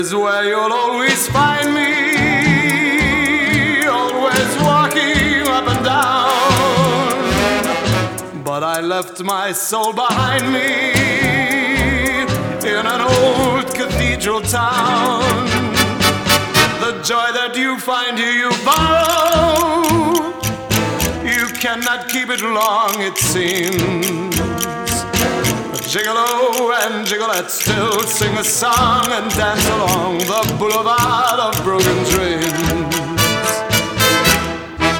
Жанр: Поп музыка / Соундтрэки / Джаз